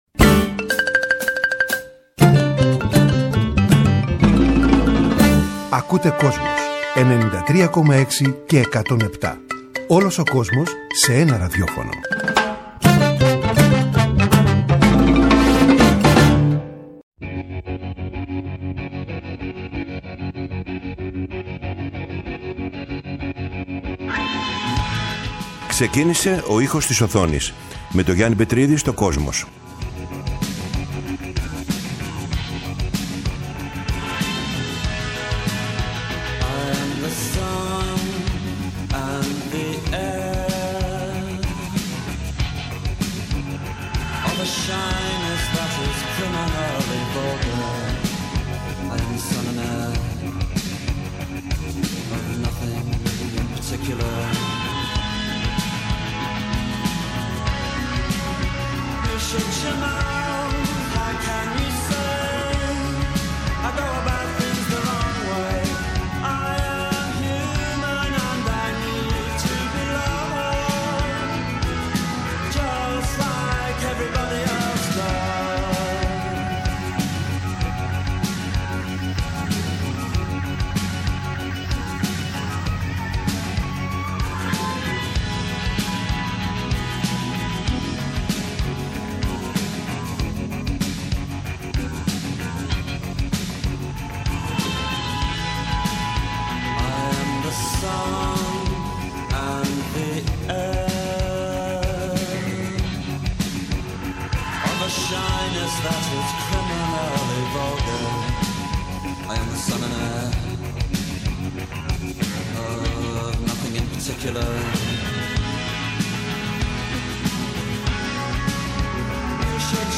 Στις εκπομπές αυτές θα ακουστούν μουσικές που έχουν γράψει μεγάλοι συνθέτες για τον κινηματογράφο, αλλά και τραγούδια που γράφτηκαν ειδικά για ταινίες.